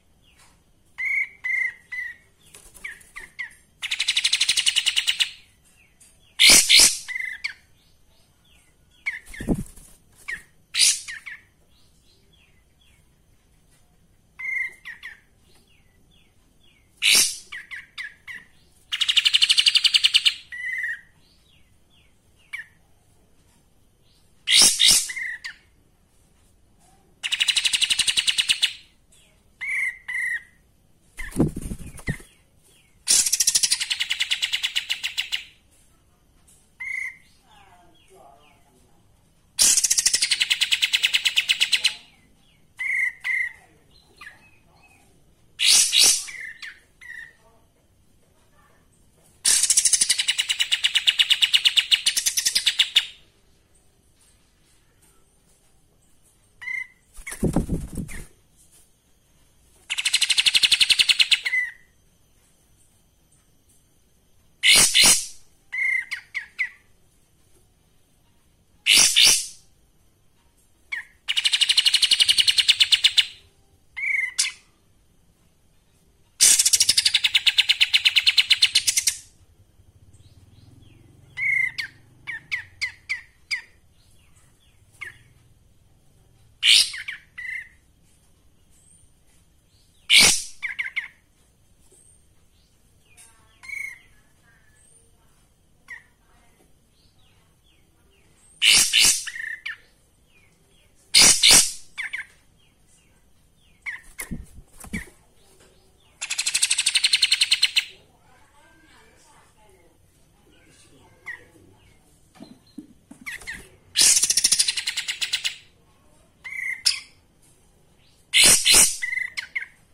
Suara Burung Kapas Tembak Betina
Kategori: Suara burung
suara-burung-kapas-tembak-betina-id-www_tiengdong_com.mp3